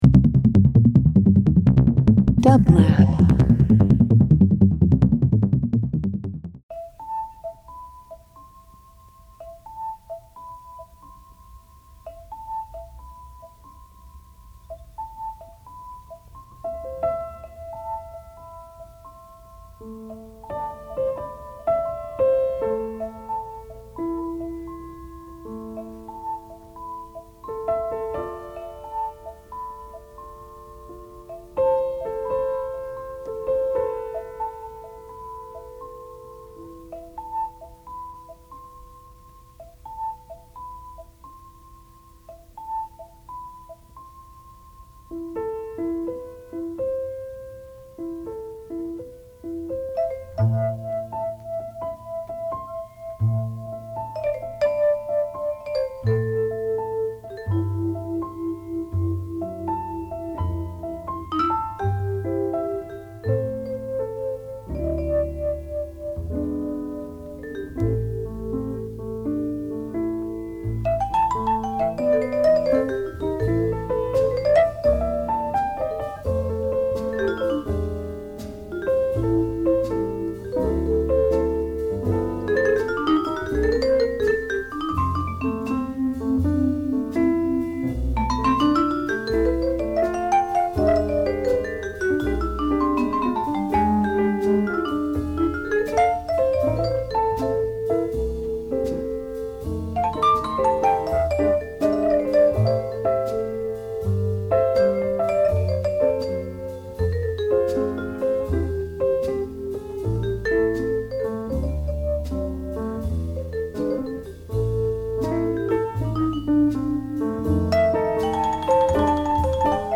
Jazz Soul